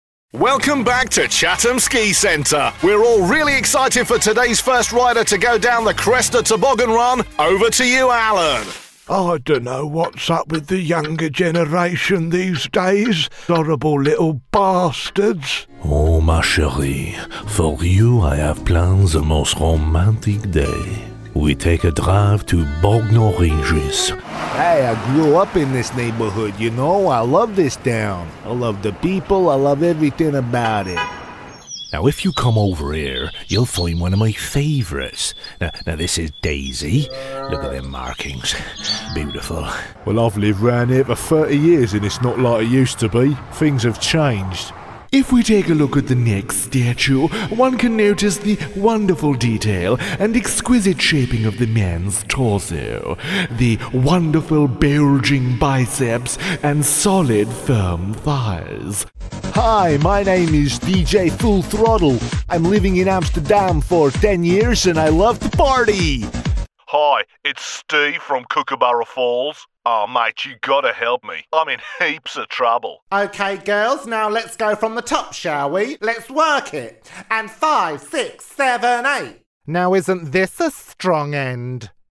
Current, relatable and versatile London voice. Characters, accents and impressions a specialty.
britisch
Sprechprobe: Sonstiges (Muttersprache):